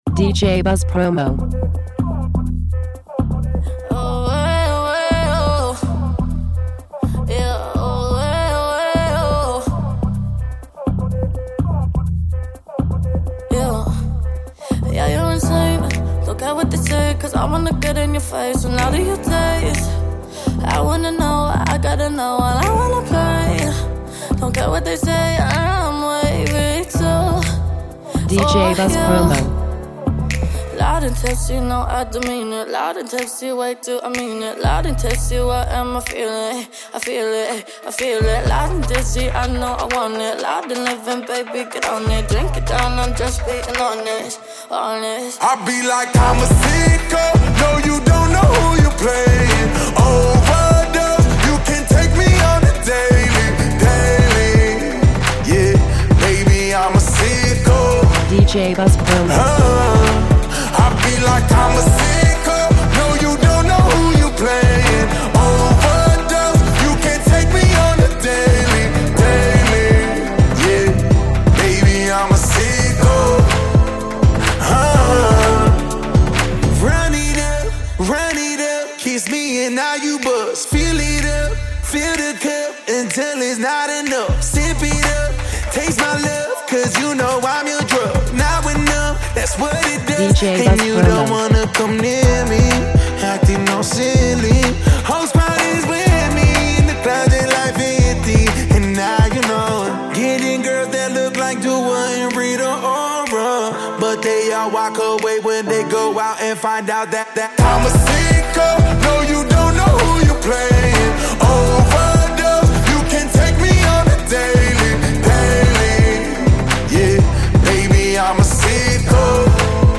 Original Mix